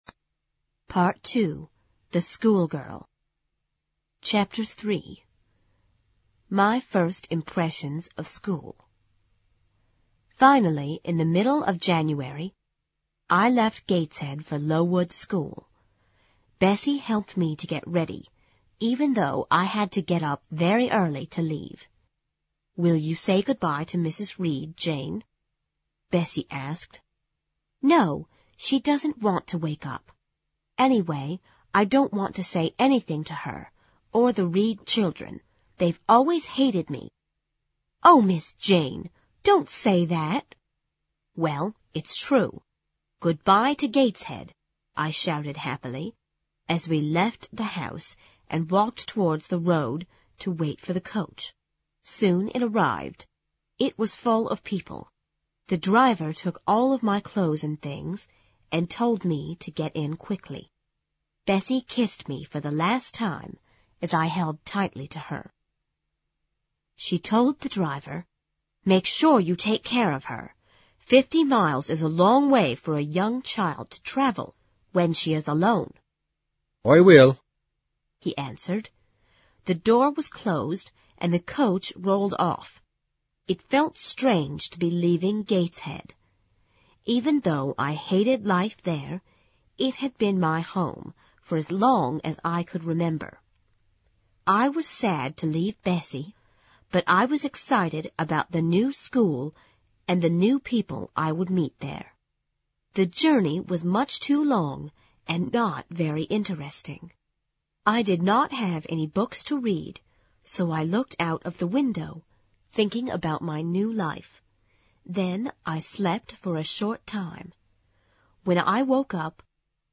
有声名著之简爱Jene Eyer Chapter3 听力文件下载—在线英语听力室